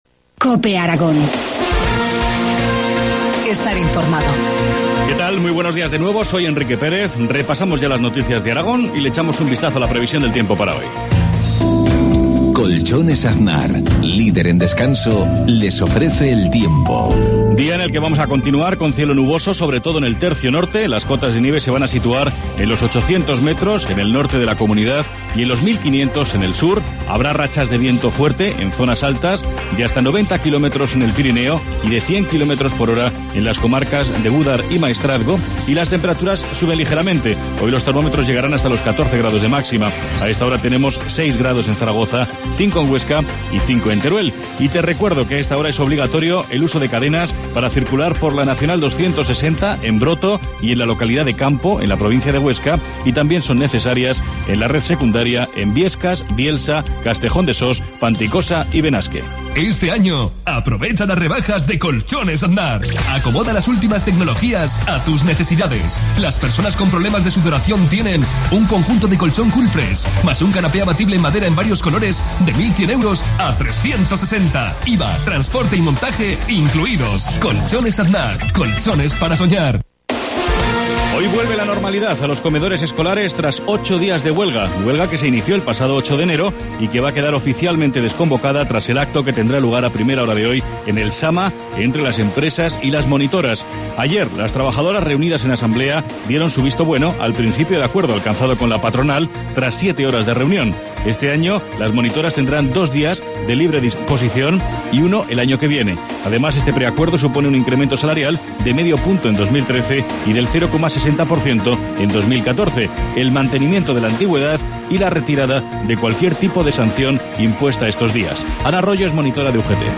Informativo matinal, viernes 18 de enero, 7.53 horas